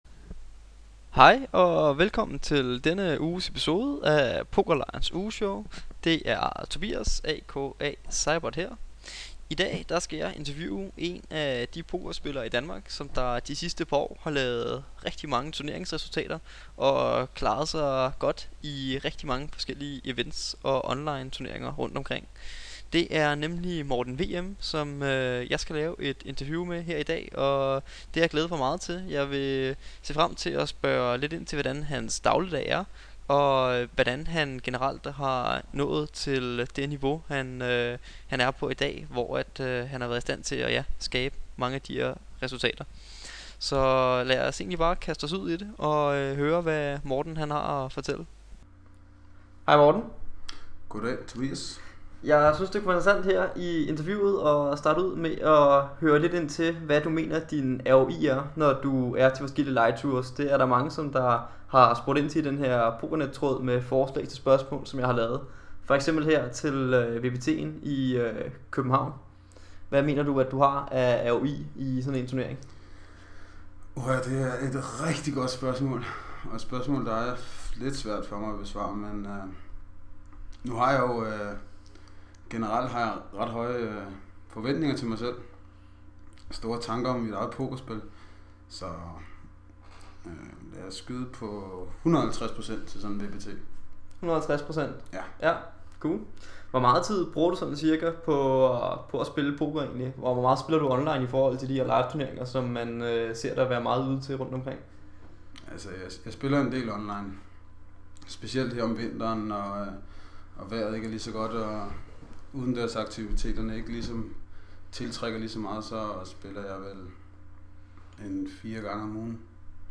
De to værter har siden den første podcast udkom i sommeren 2012 haft en lang række forskellige pokerprofiler med som gæster, og det har givet anledning til mange spændende snakke om hvad der rører sig i dansk og international poker.